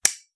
switch3.wav